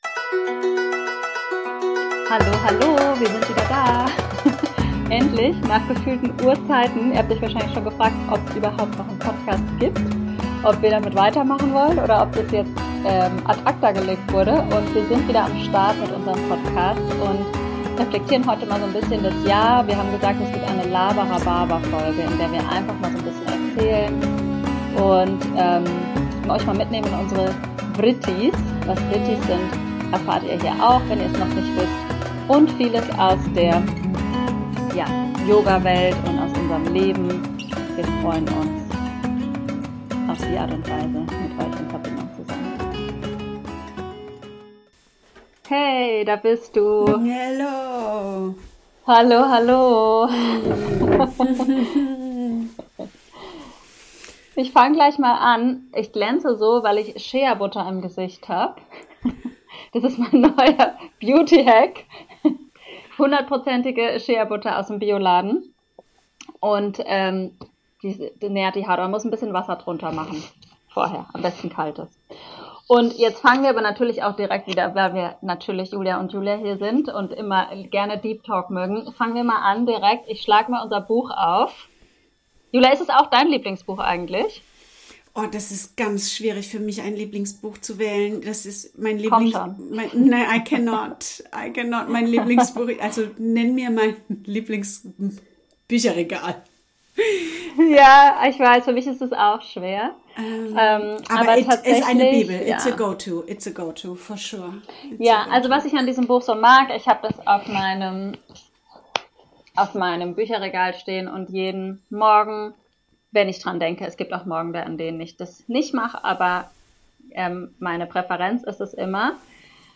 Wir wollten mal eine Folge aufnehmen, in der wir einfach nur quatschen.